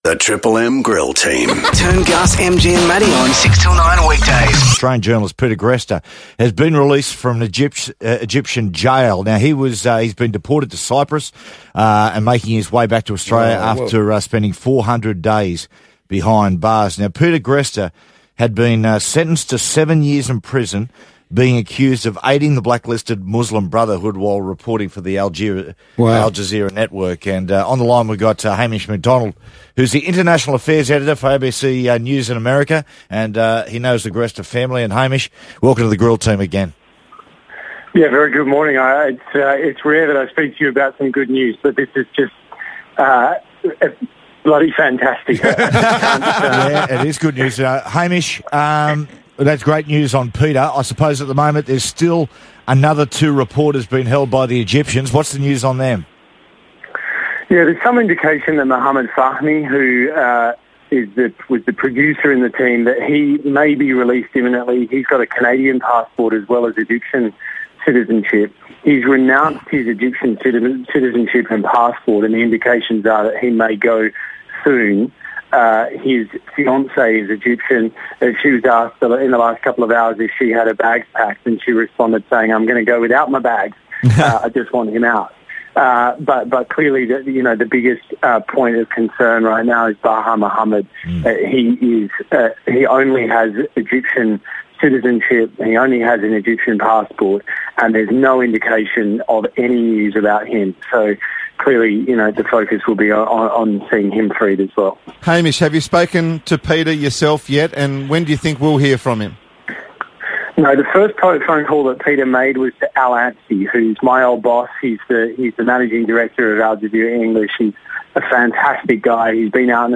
ABC News' Hamish Macdonald speaks to the Triple M Grill Team about Peter Greste's release from an Egyptian jail.